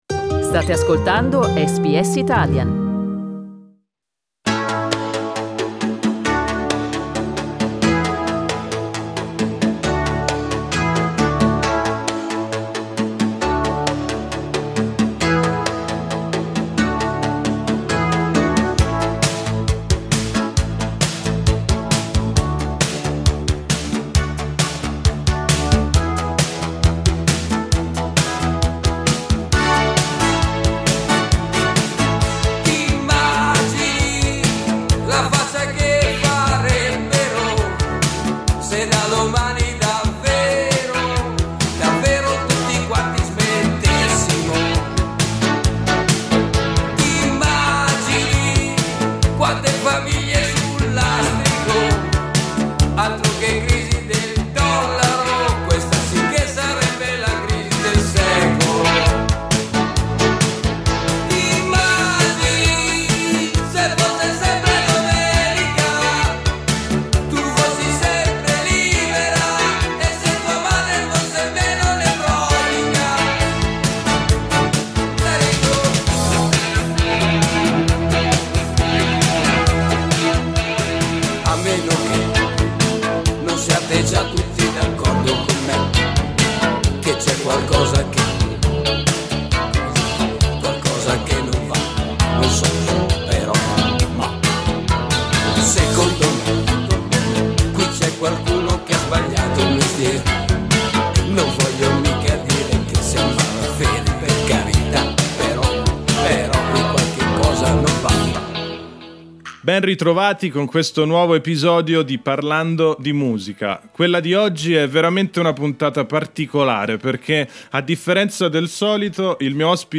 Writer, journalist and music critic, he talks to SBS Italian in the unprecedented role of interviewee.